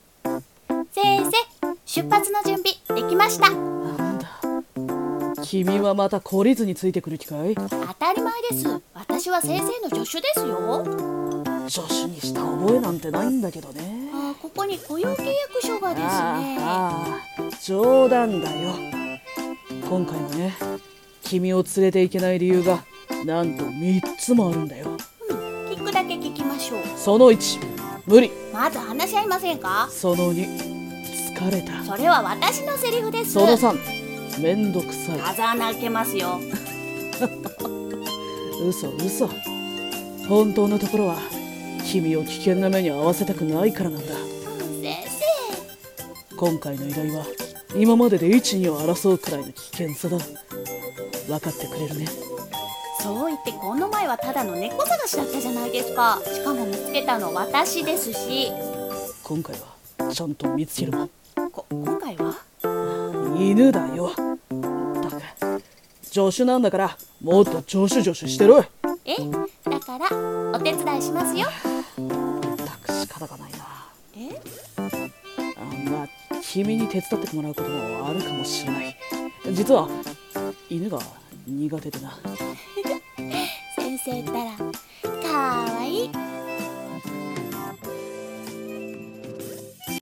【声劇】『不器用探偵』